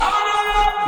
Oh No No.wav